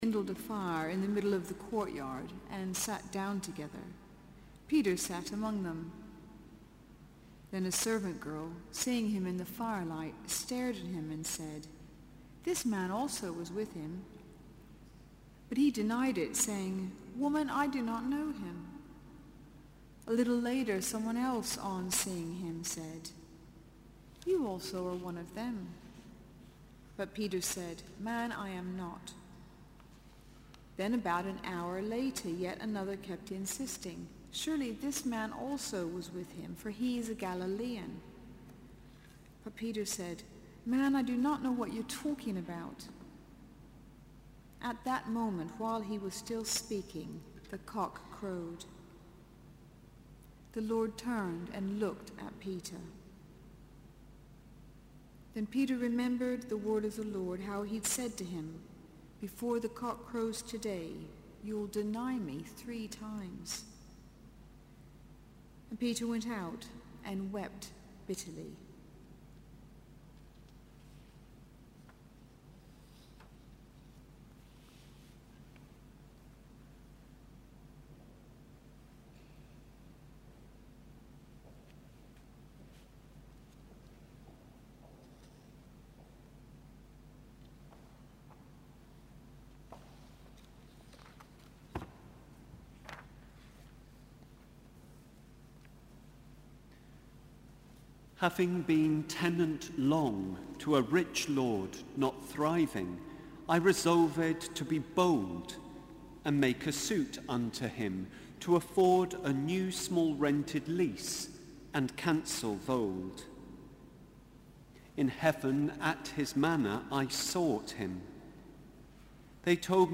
Sermon: Good Friday 2014 - Part 3